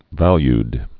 (vălyd)